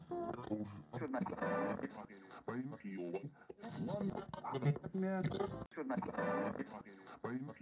无线电特效
标签： 126 bpm Hip Hop Loops Fx Loops 1.28 MB wav Key : Unknown
声道立体声